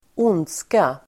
Uttal: [²'on:dska]